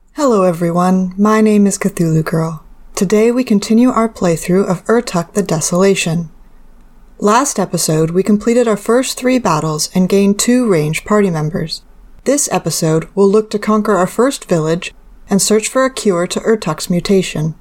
I’m currently using a Blue Snowball iCE USB mic and recording at my desk.
I recorded a new clip with some delay at the beginning for getting the noise profile:
This sounds much better, and with the correct noise reduction has more of those warmer tones I was looking for.
After doing the above changes, the audio now sounds like I’m a bit too close to the mic, but moving the mic back doesn’t seem to fix this (and just makes background noise worse) and I think it’s the second Normalize gain that’s causing that.